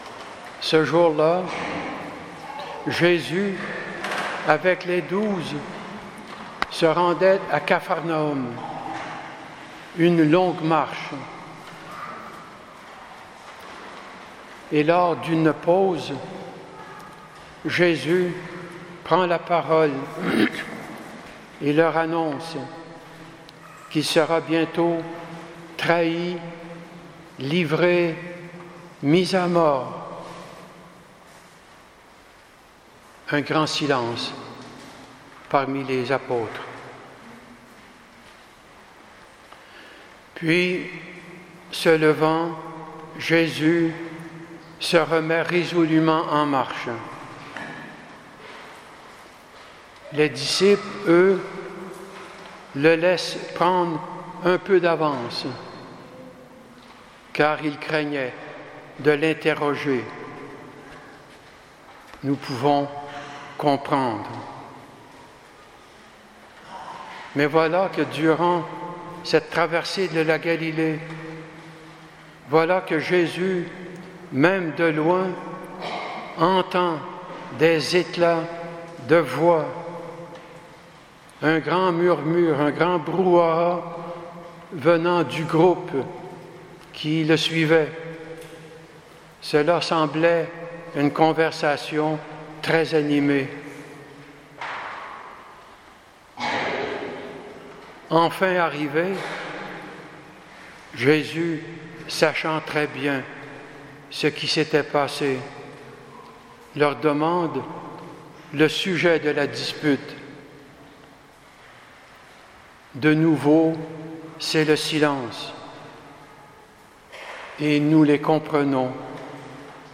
Homélie du 25ème dimanche du Temps Ordinaire